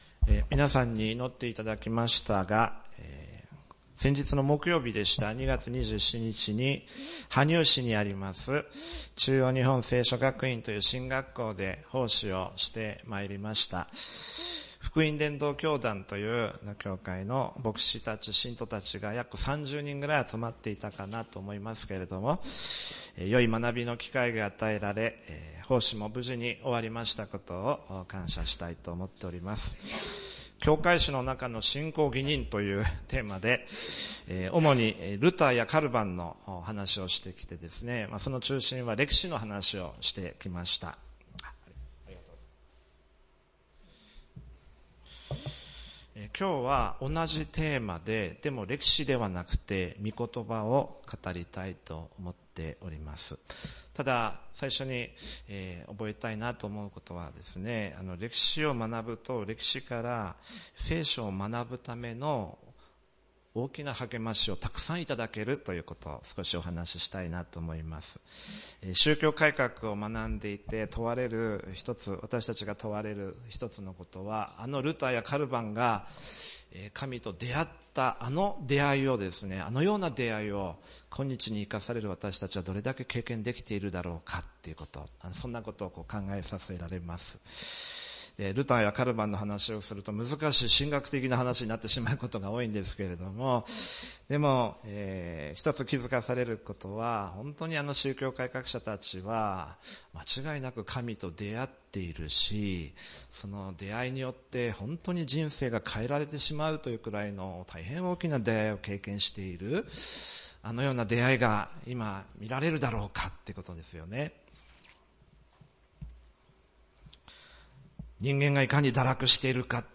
この説教は、キリスト教の核心的な教理である「信仰義認」について、分かりやすく解説しています。神の愛と恵みの大きさを強調し、それに応えて生きることの重要性を説いています